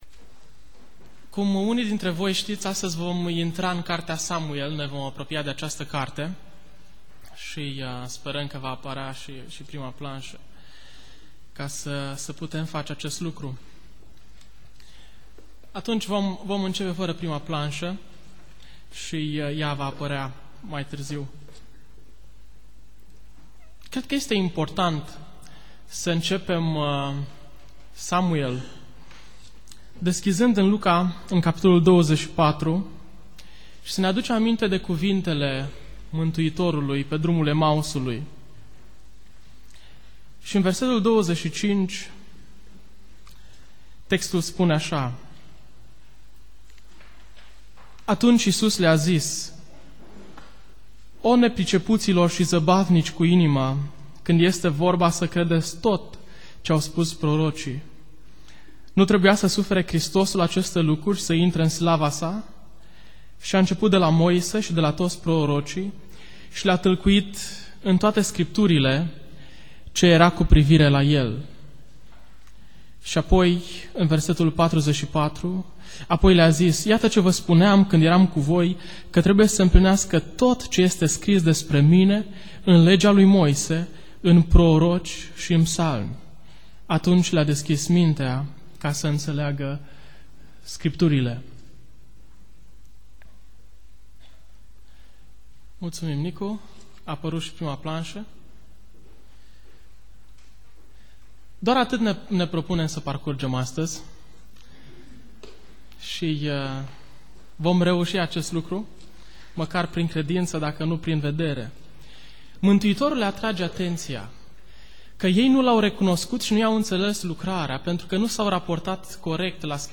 Biserica Neemia - Portal materiale - Predica 1 Samuel Introducere